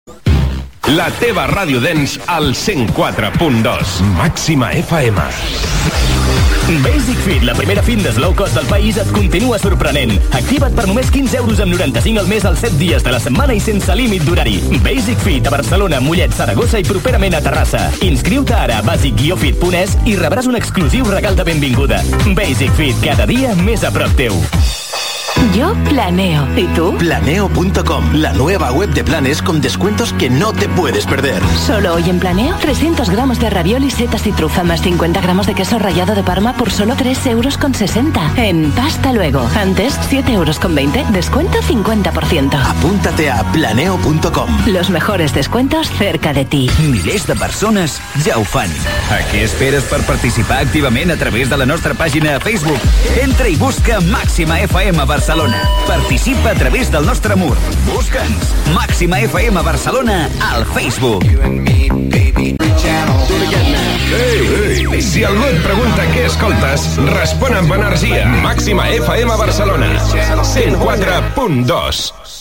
Identificació, publicitat, pàgina Facebook de l'emissora, identificació.
FM